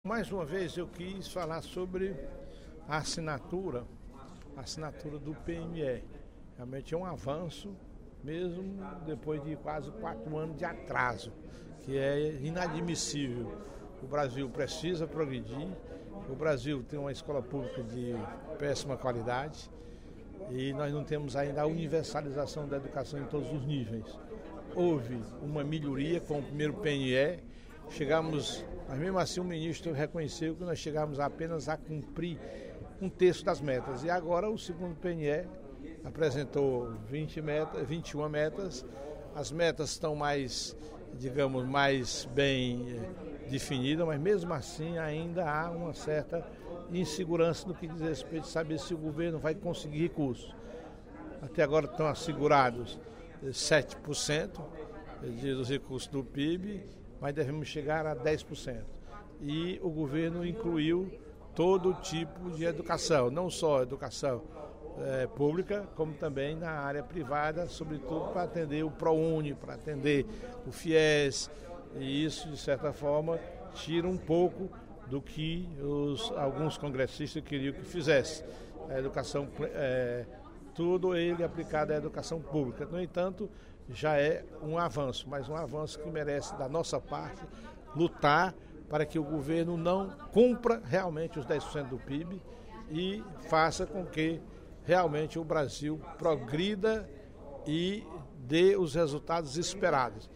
O deputado Professor Teodoro (PSD) fez pronunciamento nesta terça-feira (10/06), durante o primeiro expediente da sessão plenária da Assembleia Legislativa, para lembrar que foi finalmente aprovado o segundo Plano Nacional de Educação.